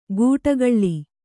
♪ gūṭagaḷḷi